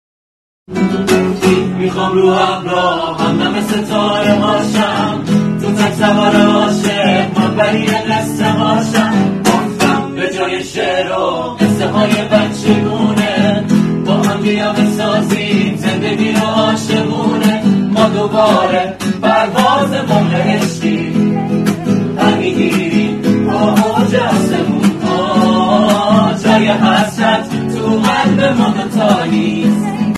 اجرای گروهی با گیتار